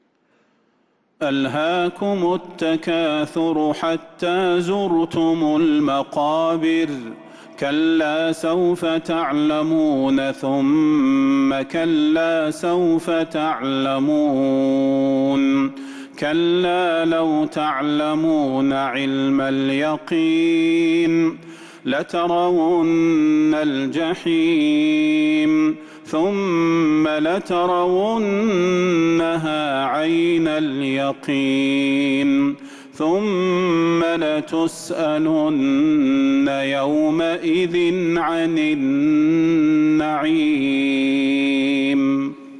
سورة التكاثر | صفر 1447هـ > السور المكتملة للشيخ صلاح البدير من الحرم النبوي 🕌 > السور المكتملة 🕌 > المزيد - تلاوات الحرمين